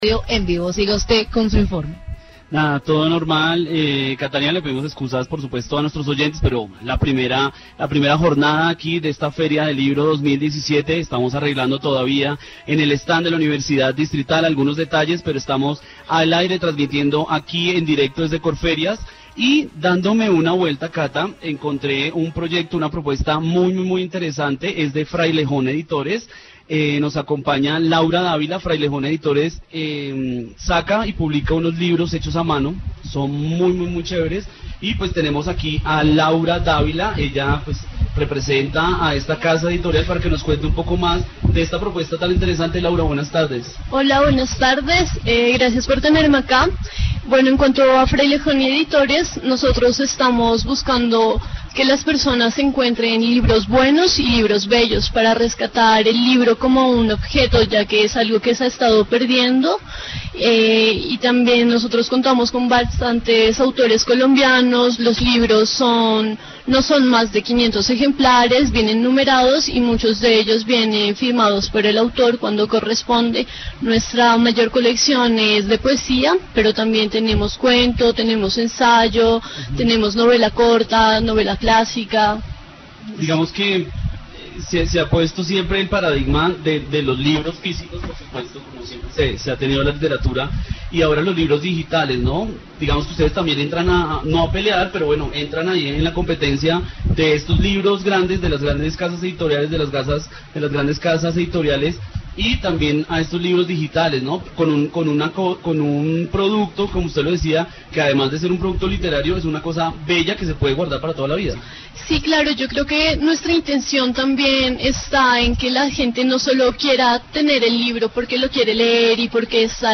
Informe desde el stand de la Universidad Distrital en la Feria Internacional del Libro de Bogotá 2017, donde se presenta el trabajo de Frailejón Editores.